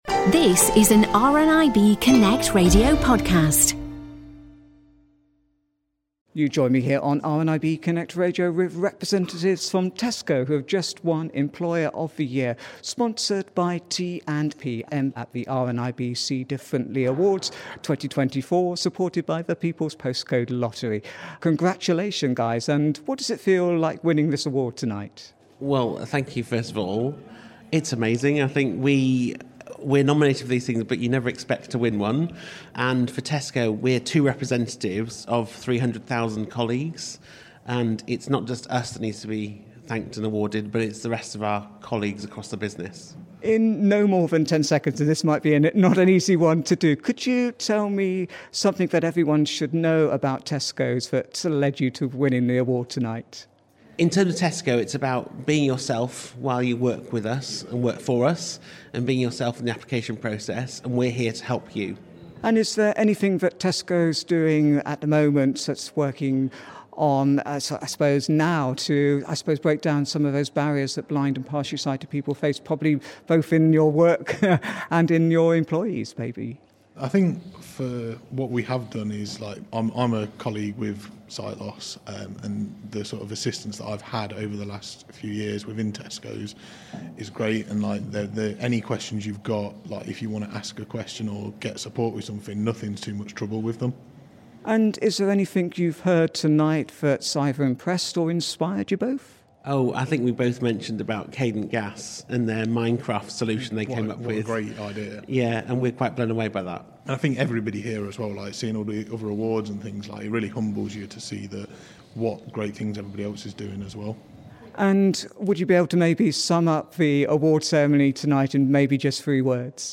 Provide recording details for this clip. Tesco won Employer Of The Year Award sponsored by T&Pm at the glitzy event and spoke with RNIB Connect Radio.